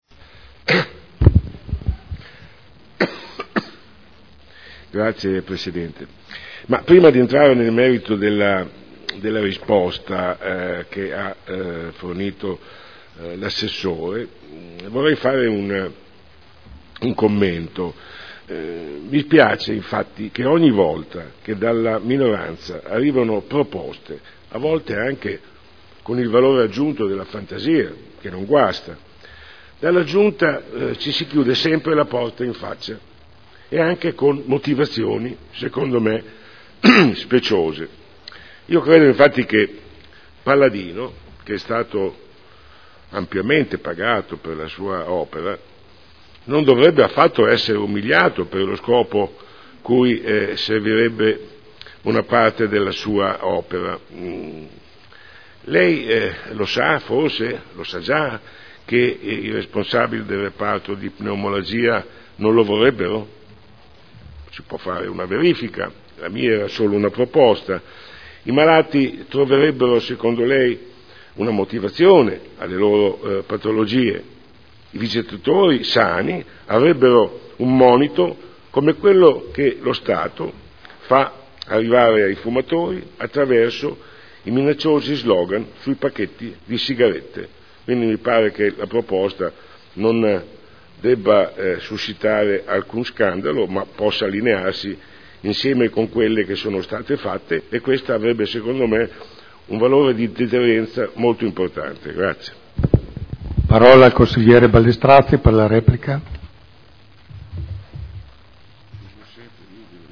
Sandro Bellei — Sito Audio Consiglio Comunale
Seduta del 27 febbraio. Interrogazioni dei consiglieri Bellei e Ballestrazzi sul telo di Paladino Replica